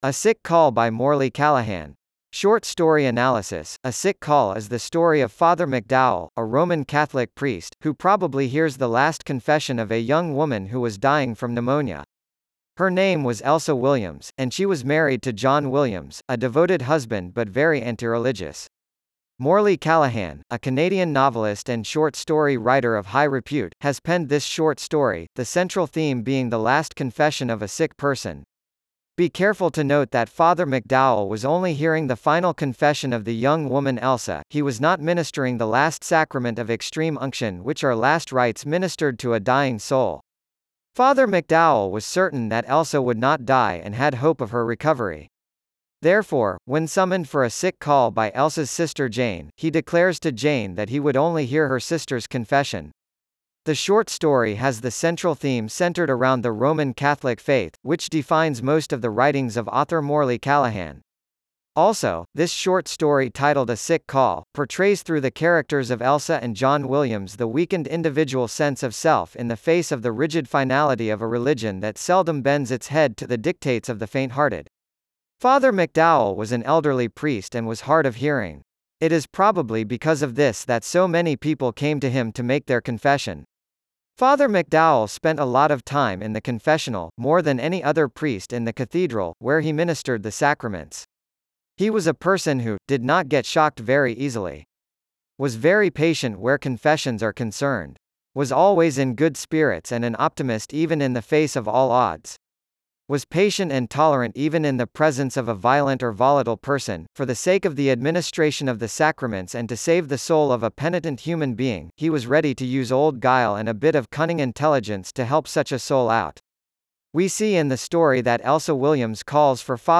A-Sick-Call-by-Morley-Callaghan-Short-Story-Analysis_en-US-Wavenet-A.wav